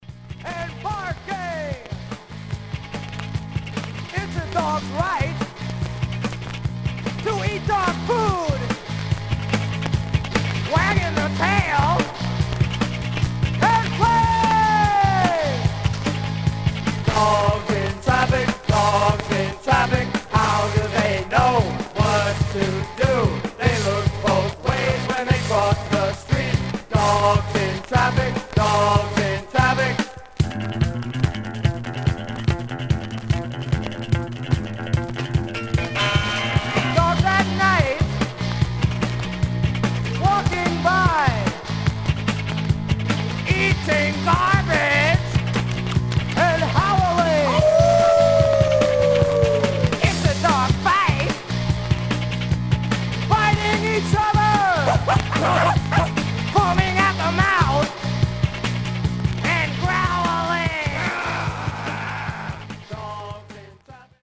Some surface noise/wear Stereo/mono Mono
Garage, 60's Punk